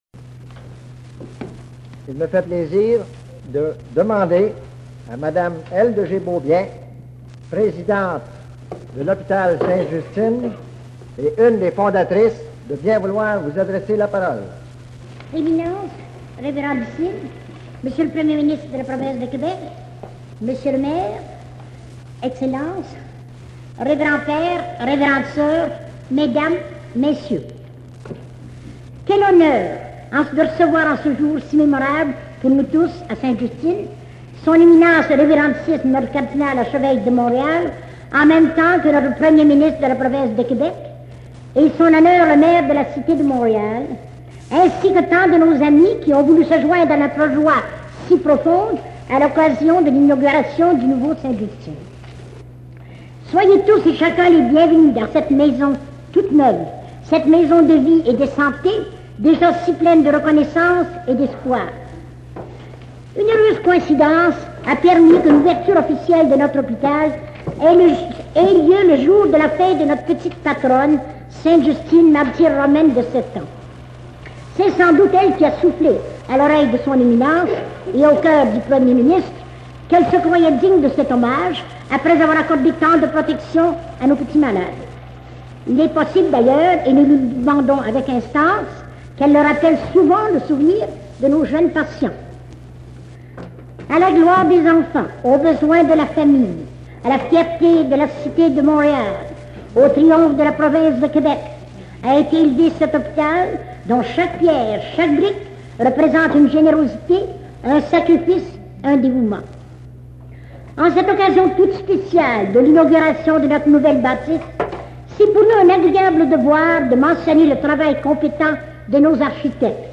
L'inauguration du nouvel édifice
Extrait du discours de Justine Lacoste Beaubien